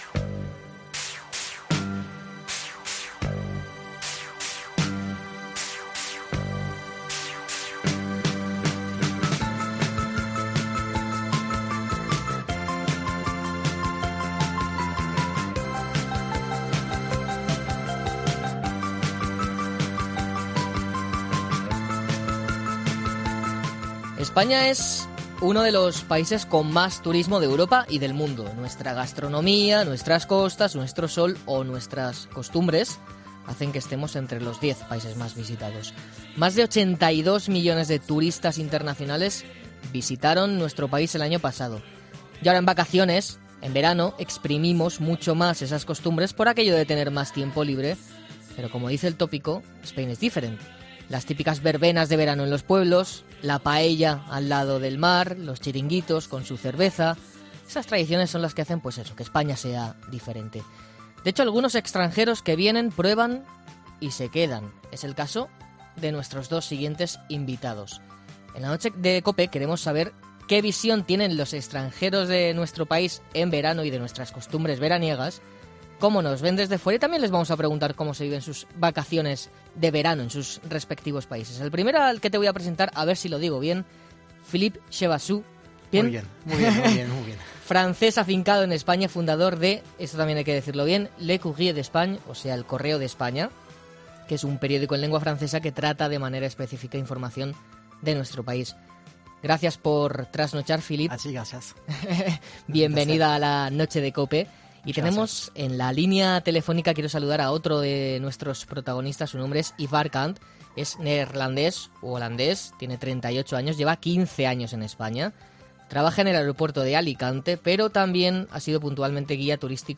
En la Noche de COPE han estado dos europeos que hace años dejaron sus países de origen para asentarse aquí durante un tiempo.